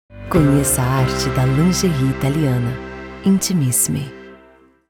Intimissimi – A Arte da Lingerie Italiana – Locução para assinatura de filme publicitário